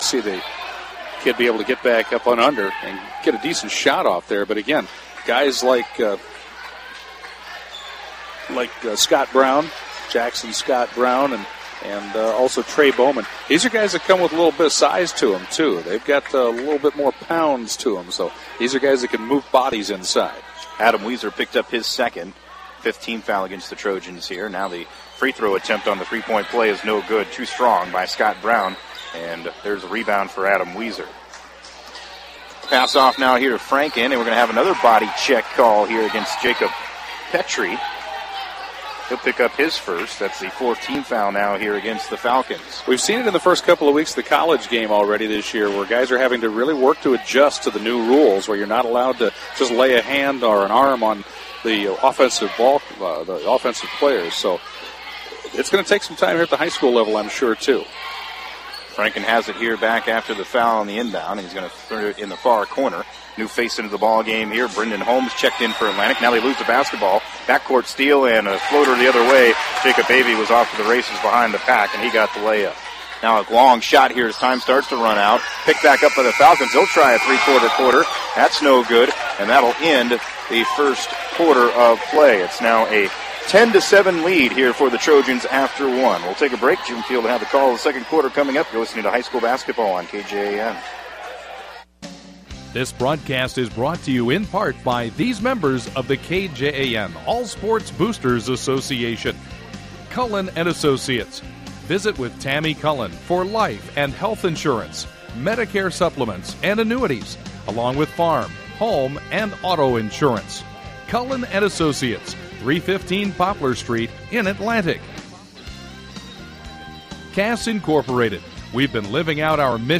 Note: Recording starts just into 1st quarter.